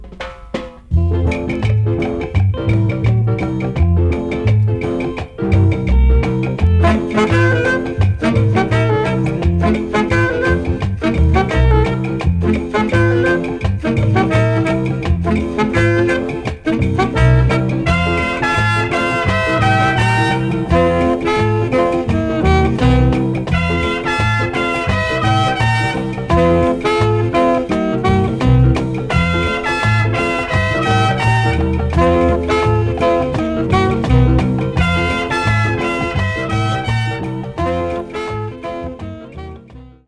OriginalInstrumental
Genre: Reggae, Rocksteady